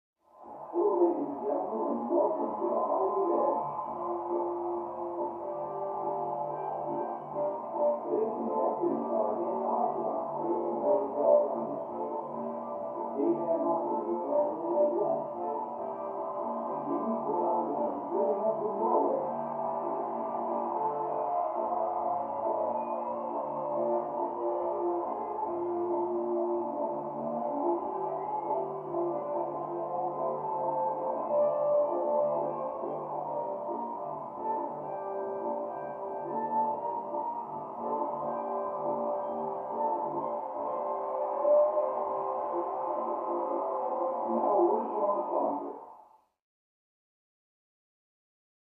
Television; Game Show Opening With Music, Announcer And Crowd. From Down Hallway.